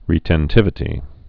(rētĕn-tĭvĭ-tē)